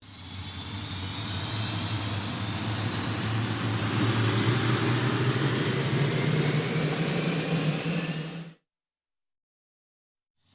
Turbine Fire Up 2
Turbine Fire Up 2 is a free nature sound effect available for download in MP3 format.
Turbine Fire Up 2.mp3